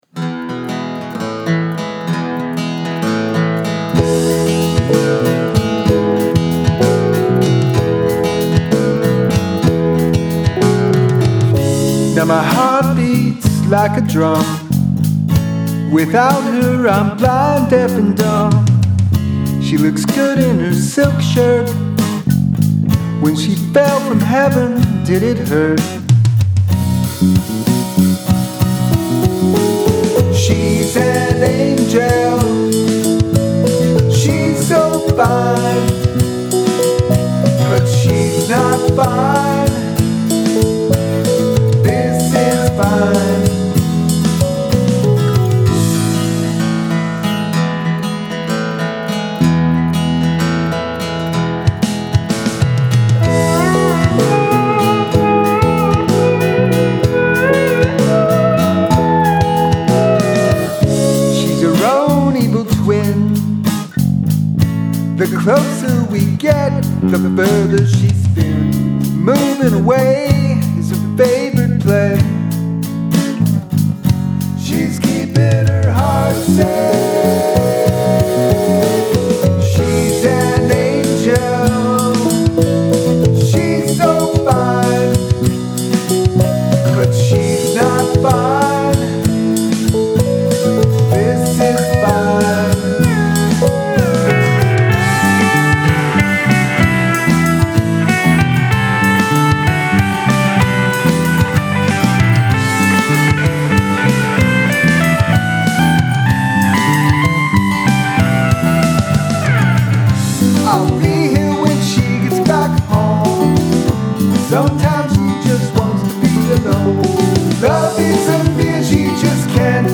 Drums
Slide Guitar, Guitar Solo, Wurlitzer
Bass
Acoustic Guitars, Vocals